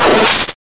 贡献 ） 分类:游戏音效 您不可以覆盖此文件。
se_extend2.mp3